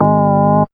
3705R ORGCHD.wav